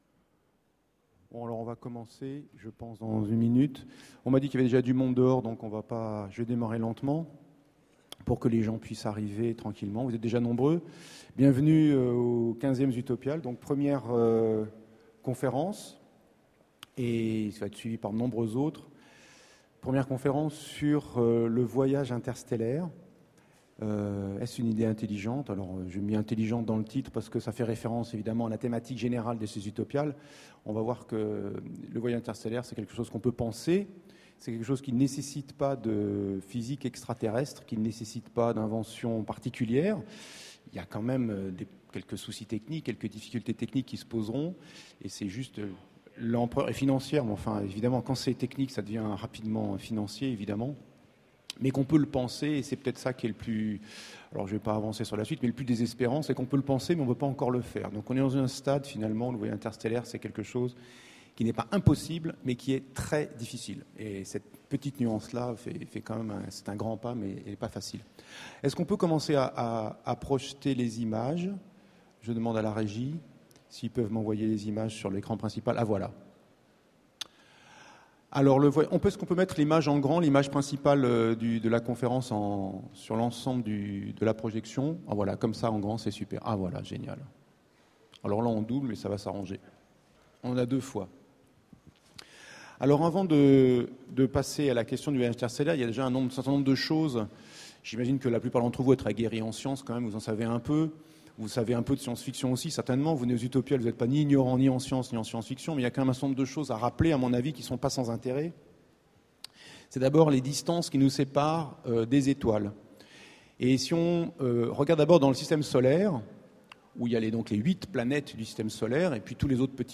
Mots-clés Espace Conférence Partager cet article